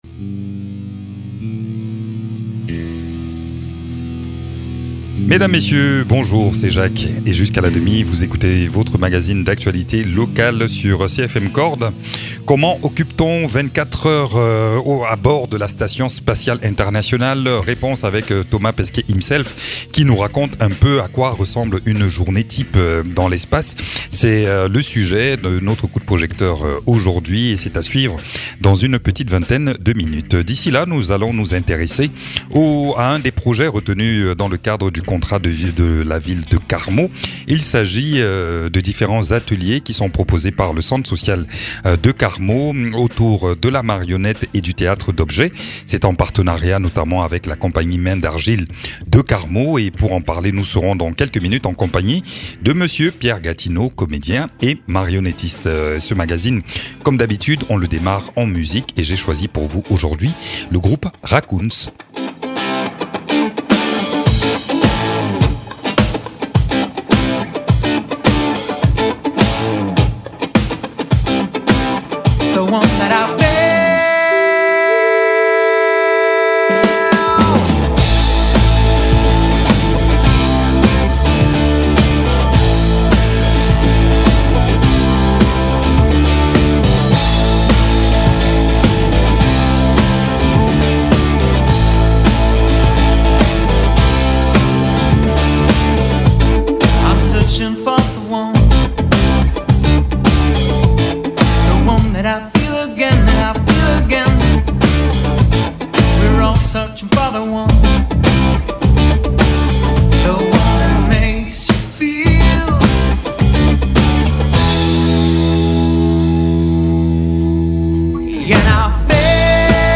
Thomas Pesquet, astronaute.